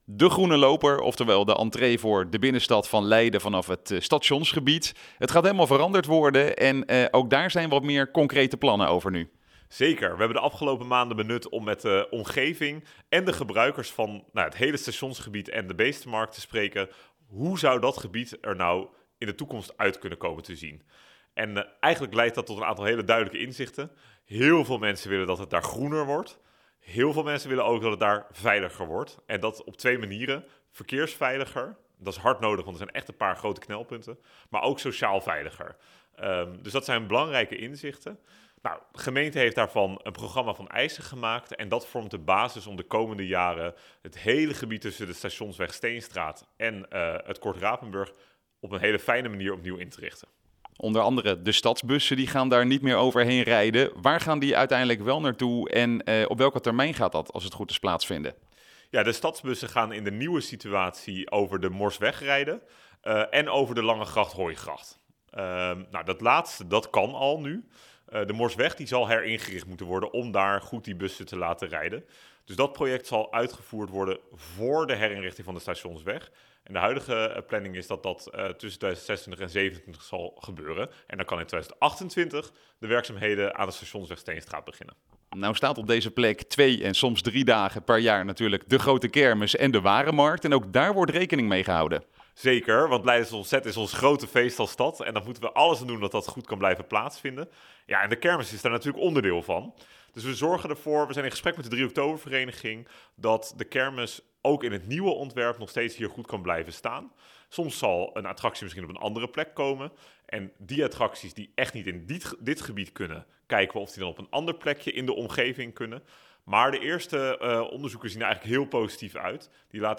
in gesprek met wethouder Ahsley North over de Groene Loper Stadsentree.